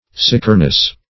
Search Result for " sickerness" : The Collaborative International Dictionary of English v.0.48: Sickerness \Sick"er*ness\, Sikerness \Sik"er*ness\, n. The quality or state of being sicker, or certain.